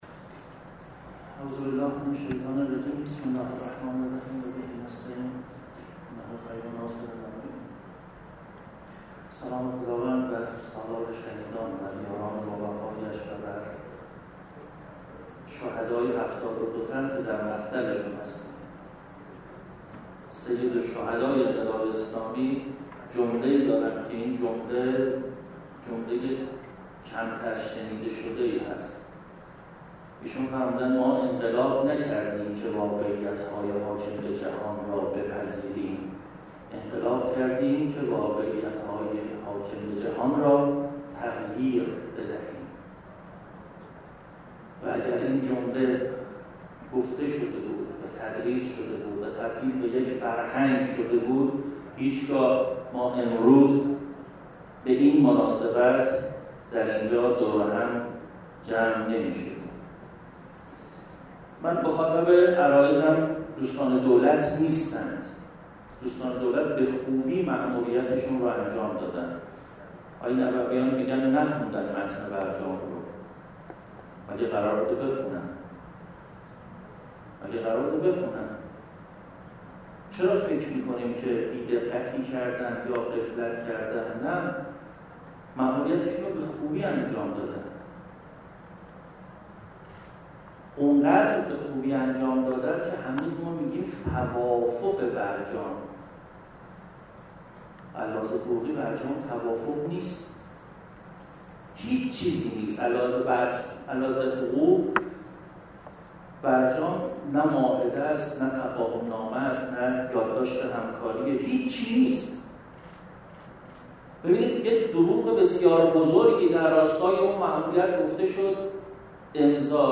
دانلود سخنرانی های مهم و جنجالی همایش “این جنازه را دفن کنید”